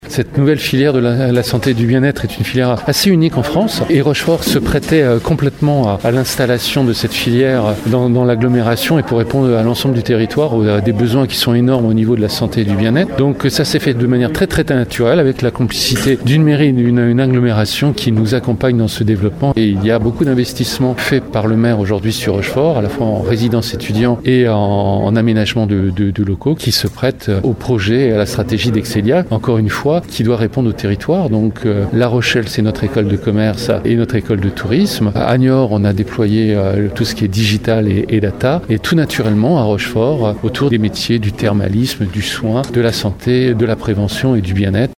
Conférence de presse ce matin à Rochefort